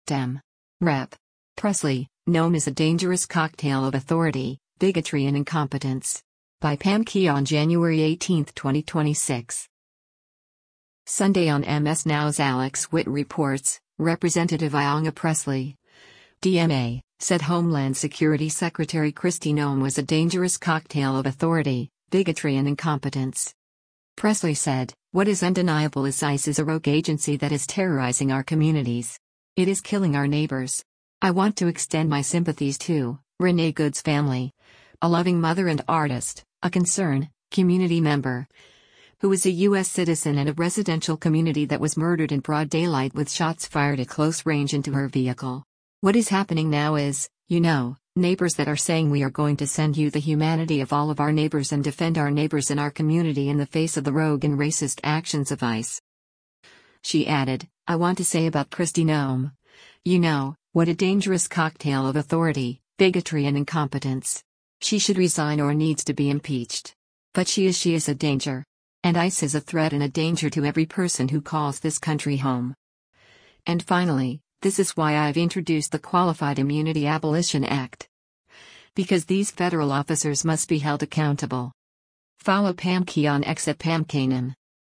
Sunday on MS NOW’s “Alex Witt Reports,” Rep. Ayanna Pressley (D-MA) said Homeland Security Secretary Kristi Noem was a “dangerous cocktail of authority, bigotry and incompetence.”